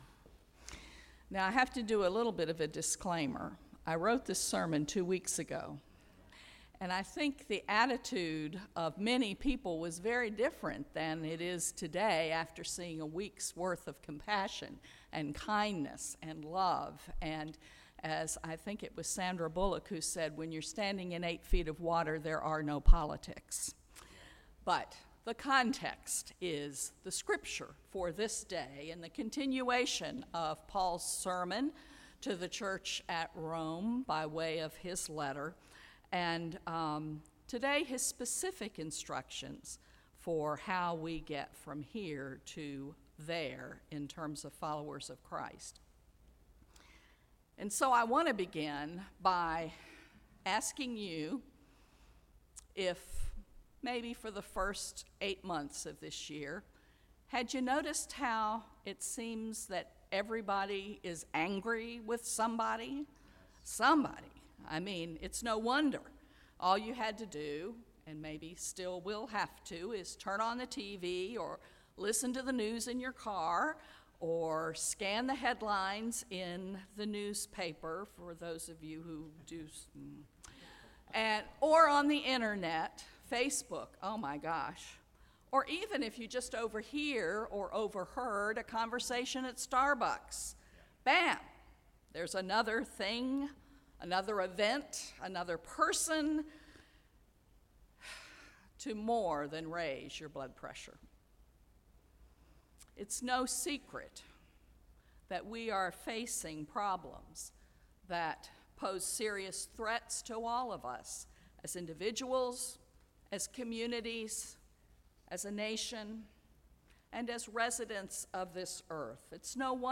Sermon-9.3.17.mp3